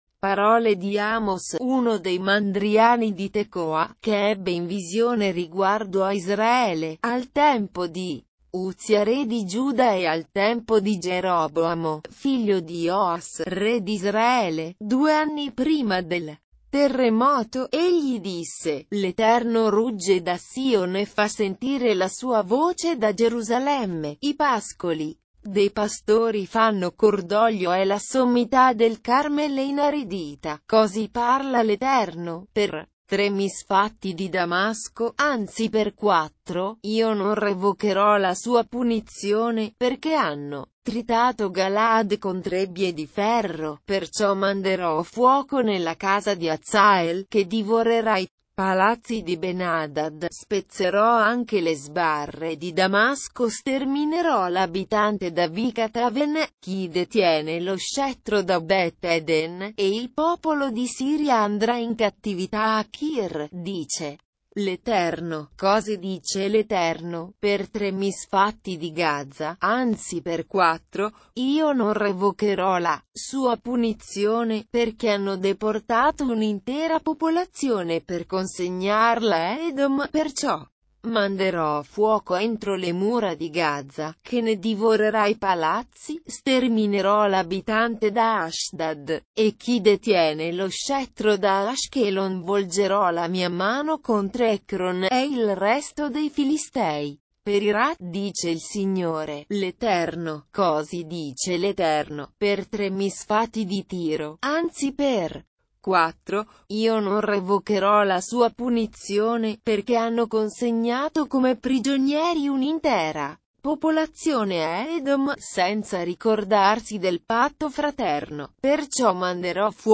Leitura na versão LND - Italiano